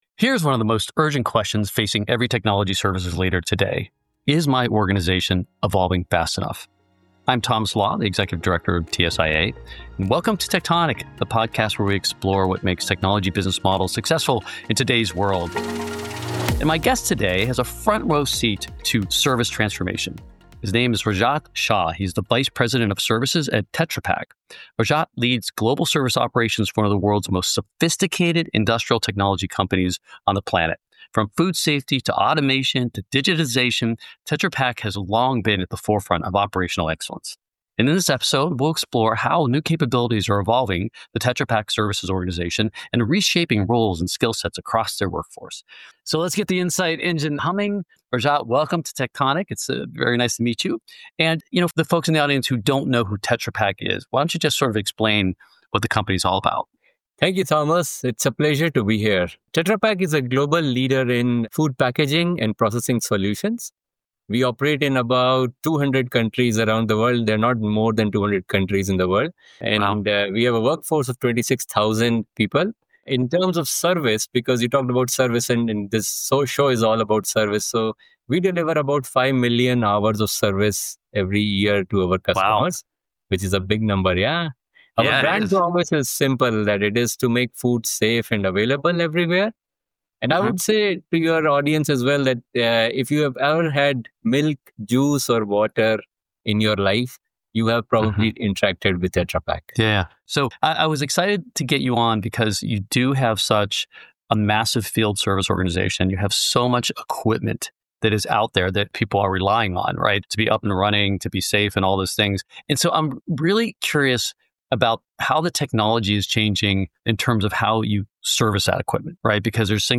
he discusses shifts in the ever-changing technology industry with tech executives, researchers, and thought leaders who share their experience and provide their perspective and data on what companies should do to stay relevant, be profitable, and succeed.